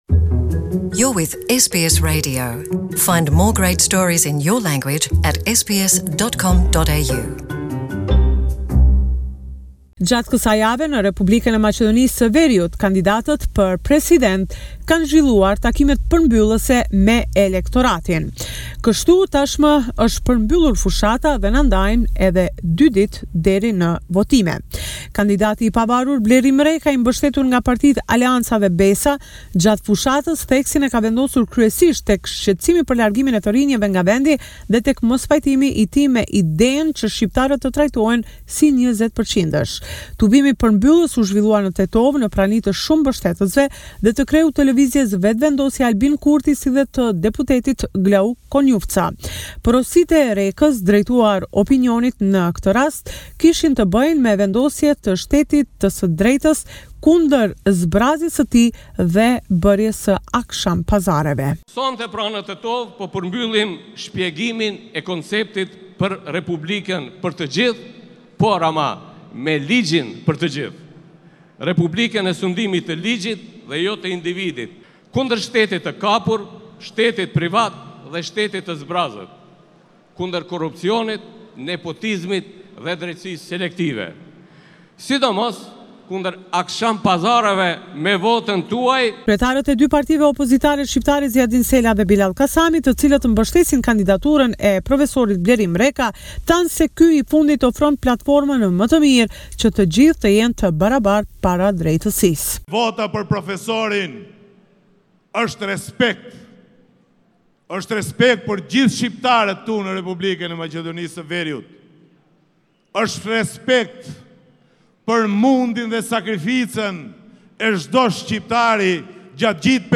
This is a report summarising the latest developments in news and current affairs in Macedonia. Campaign closed, Sunday 21 April will mark first round of voting.